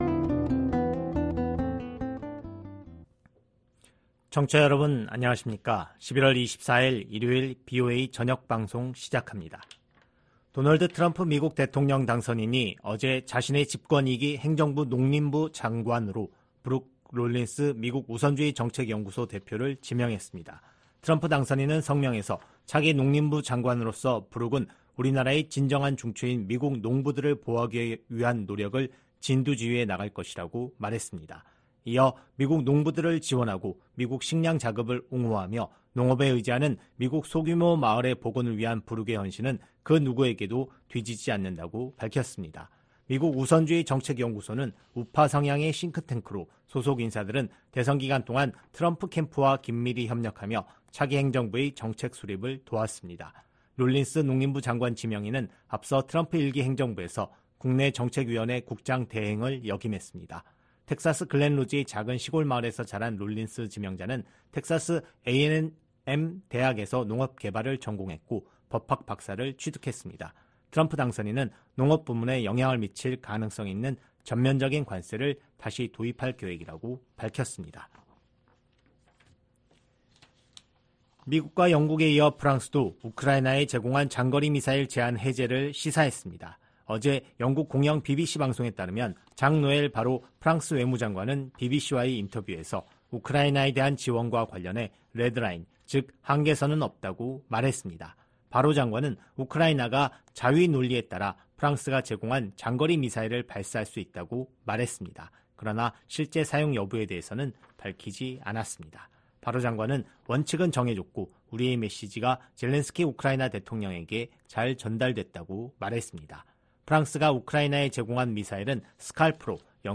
VOA 한국어 방송의 일요일 오후 프로그램 3부입니다.